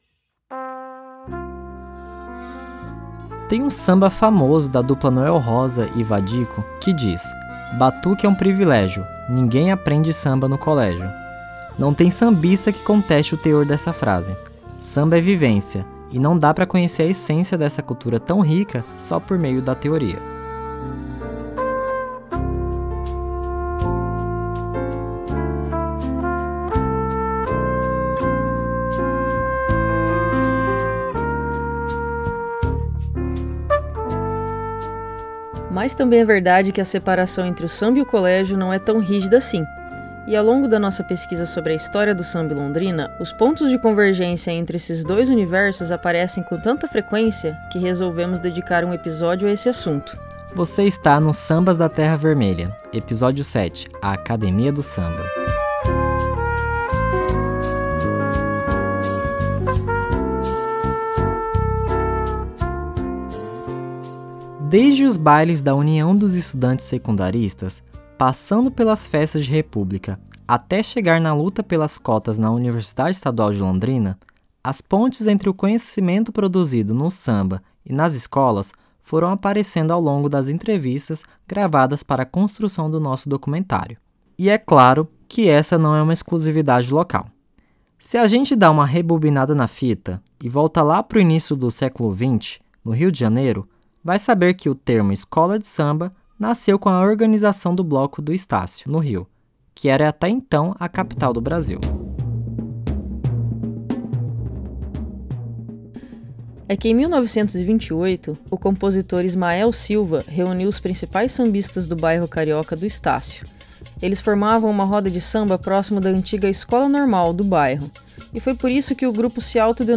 Com depoimentos emocionantes e divertidos de quem viveu intensamente essa conexão